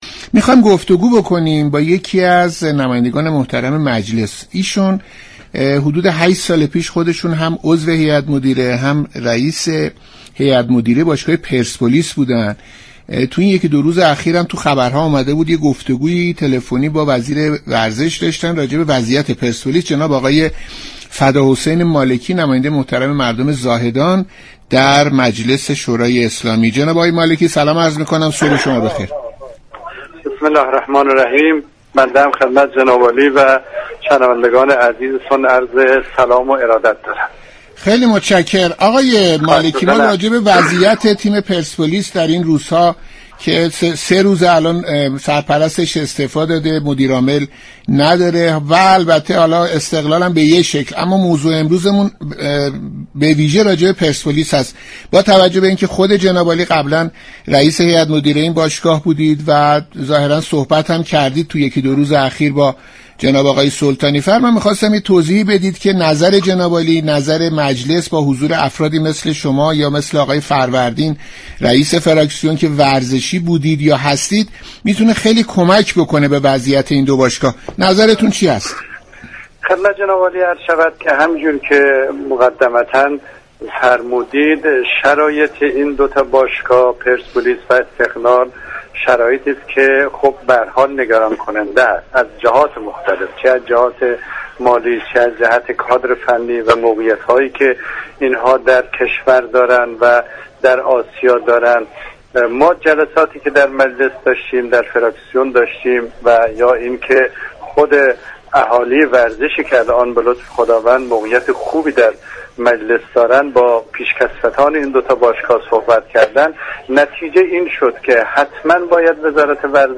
برنامه «صبح و ورزش» دوشنبه 5 آبان در گفتگو با فداحسین مالكی، نماینده مردم زاهدان در مجلس شورای اسلامی به موضوع وضعیت اخیر پرسپولیس و تماس تلفنی وی با وزیر ورزش پرداخت.